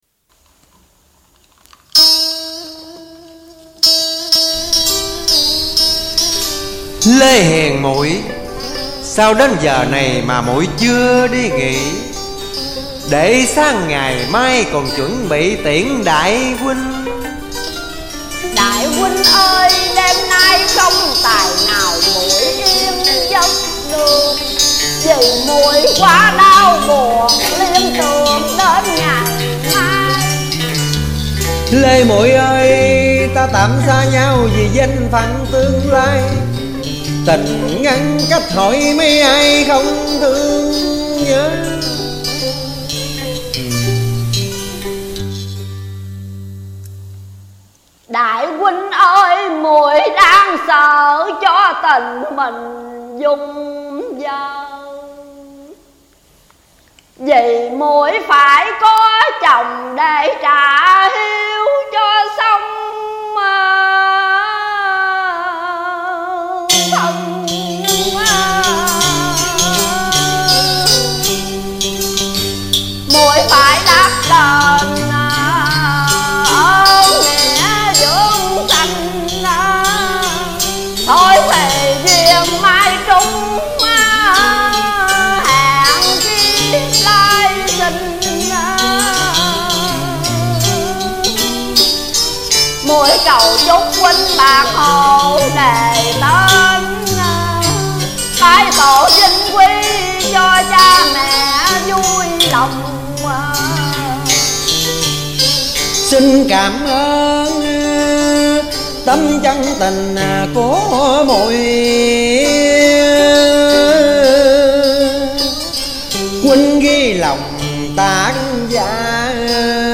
song ca